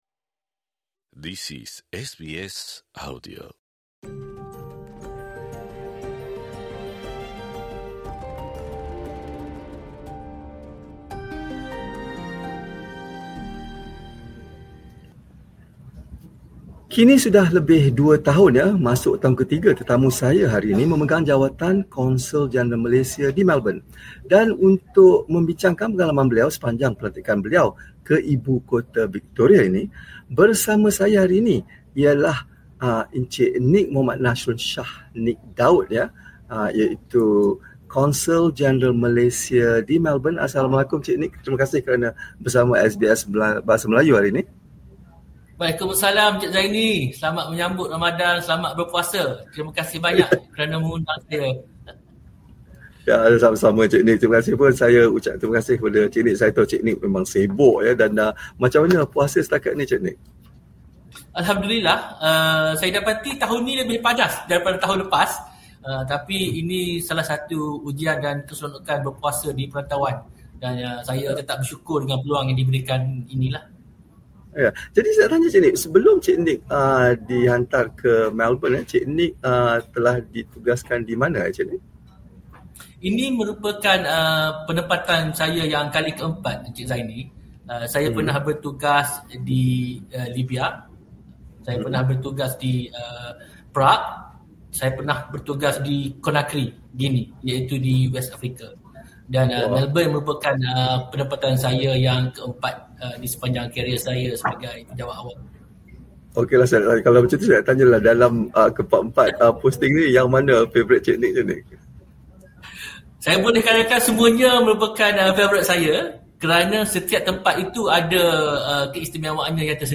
menemubual Nik Nasyron untuk mengetahui apakah hasrat dan matlamat beliau seterusnya.